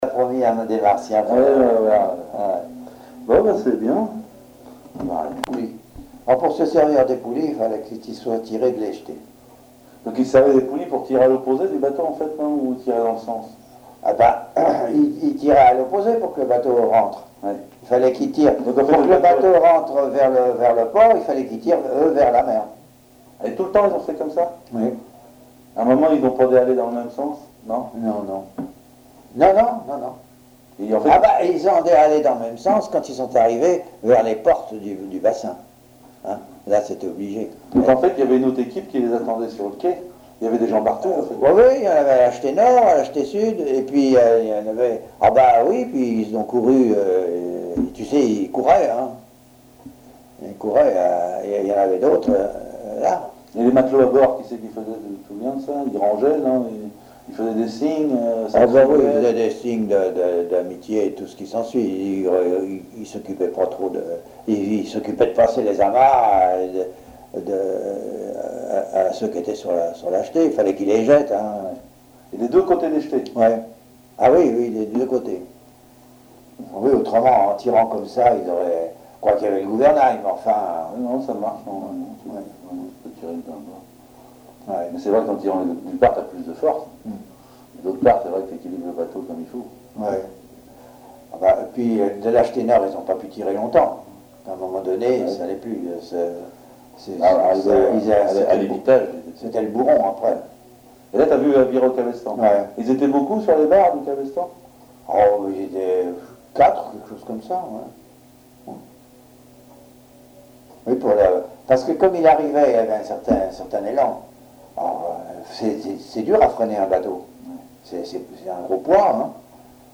Témoignages sur la construction navale à Fécamp
Catégorie Témoignage